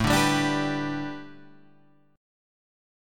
A9sus4 chord